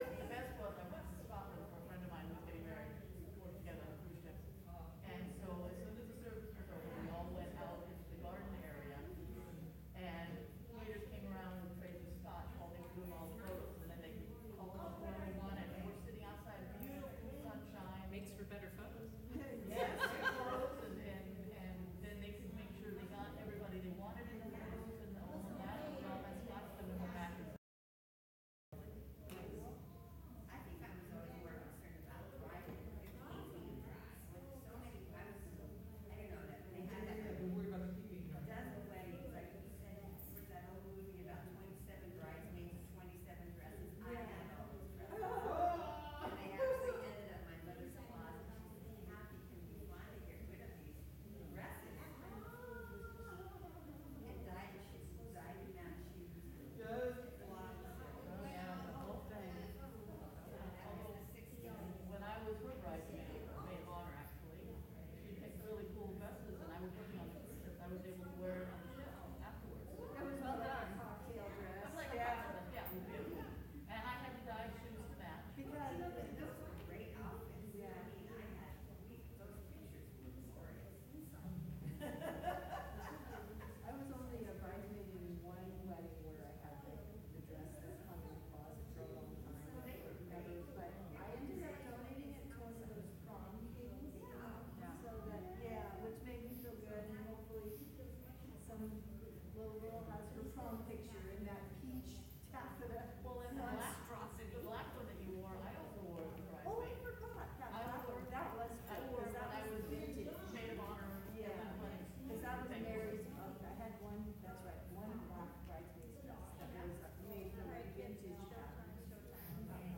Earth Day Celebration Worship Service